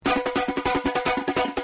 バテリア＆サンバ楽器